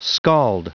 Prononciation du mot scald en anglais (fichier audio)
Prononciation du mot : scald